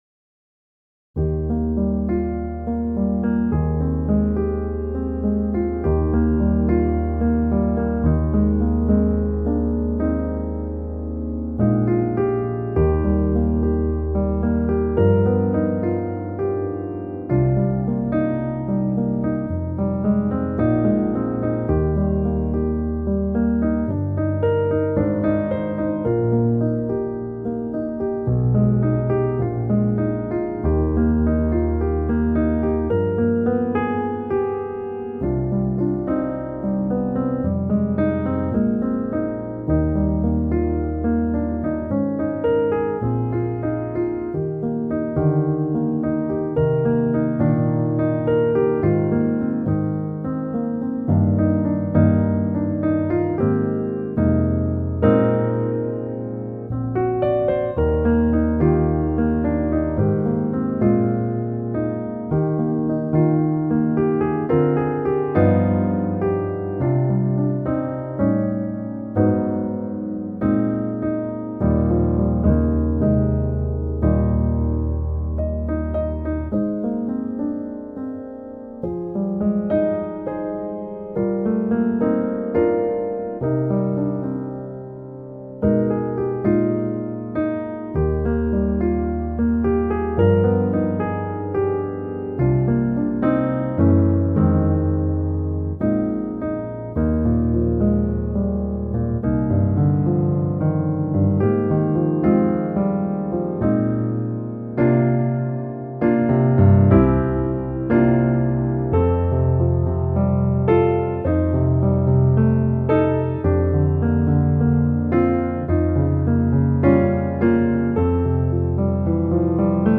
Voicing: Piano Solo Collection